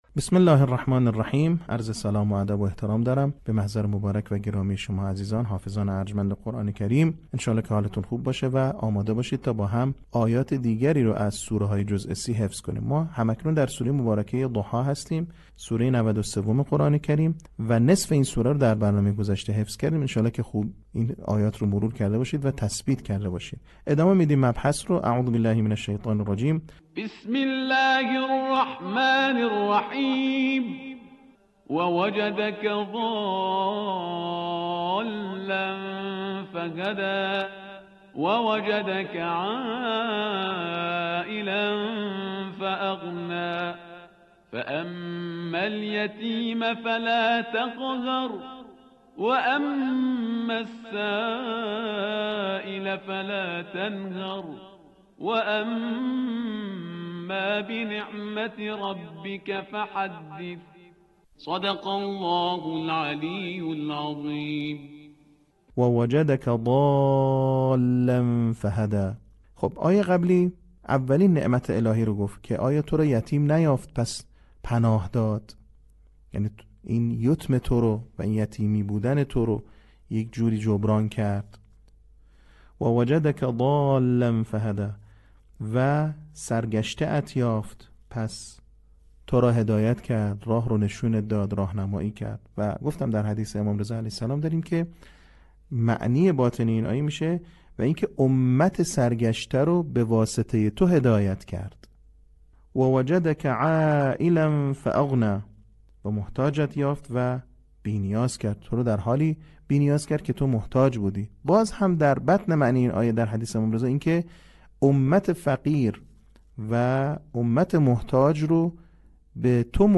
آموزش قرآن